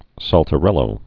(săltə-rĕlō, säl-)